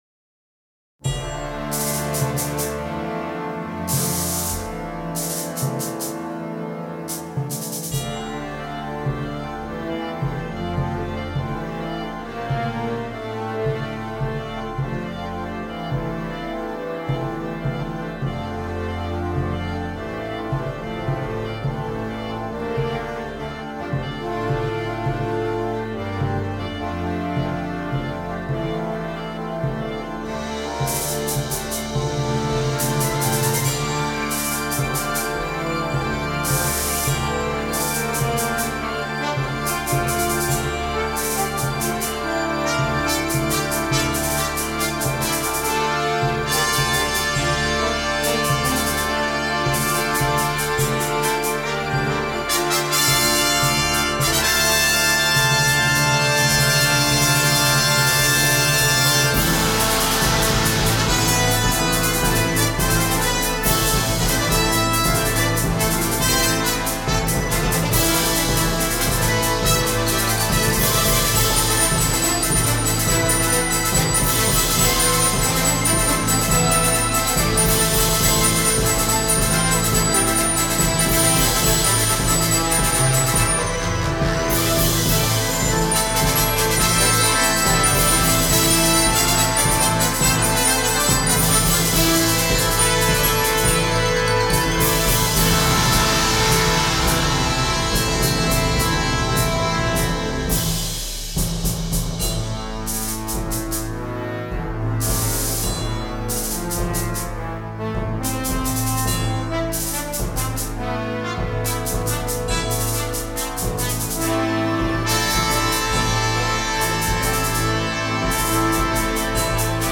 InstrumentationPiccolo
Timpani
Tubular Bells